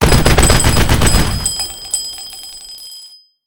machine2.ogg